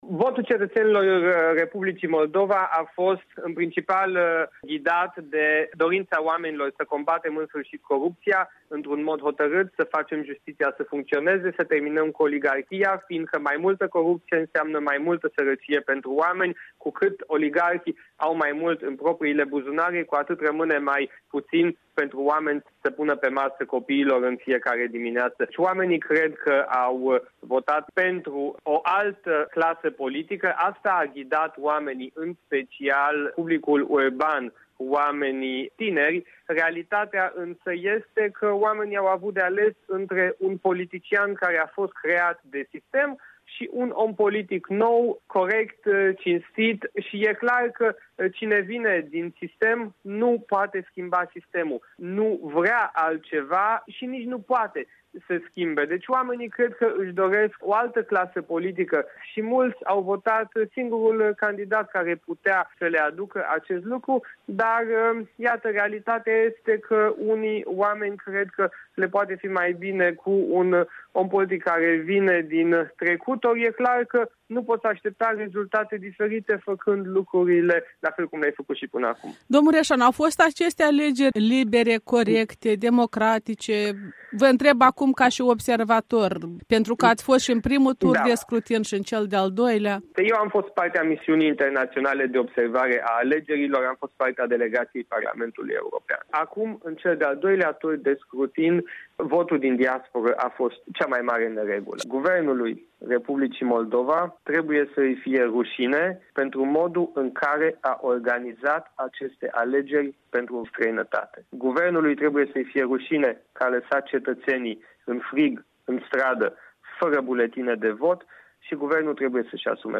În dialog cu europarlamentarul Siegfried Mureșan